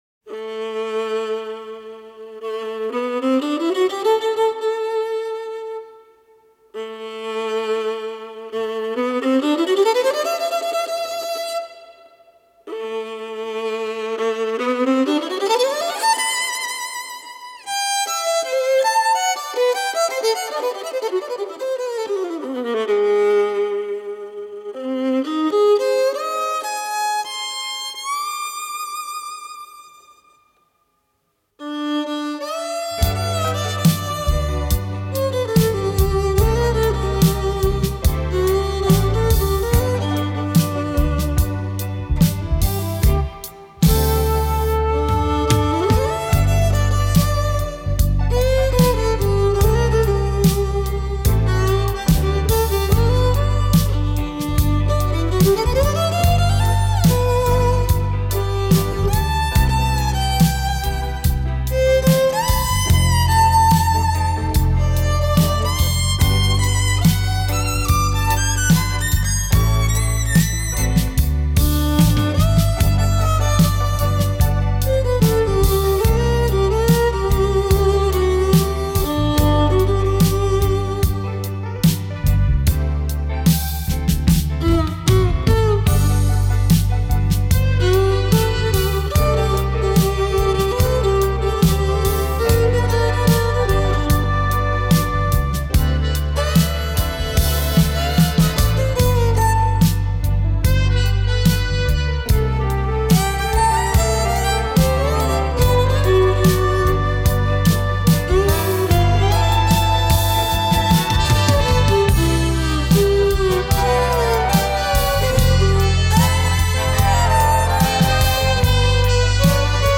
Populārā mūzika
Instrumentāls
Instrumentāls skaņdarbs